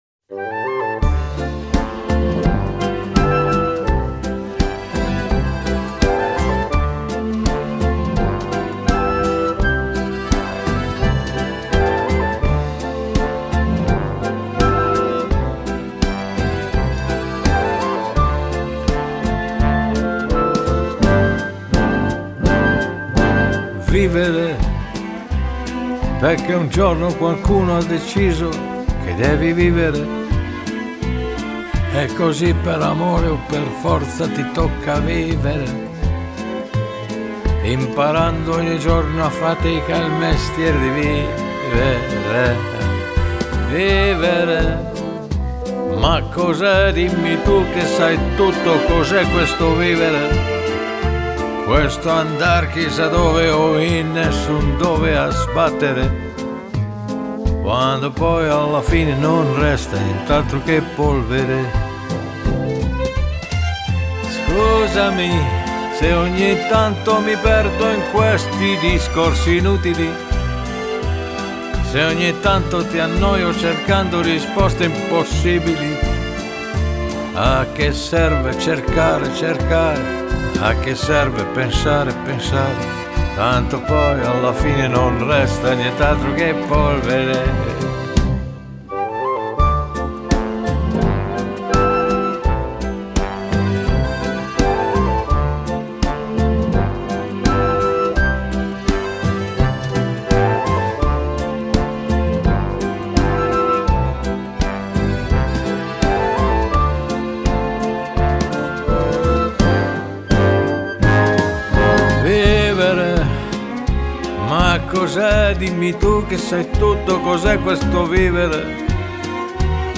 ترانه ایتالیایی Canzone Italiana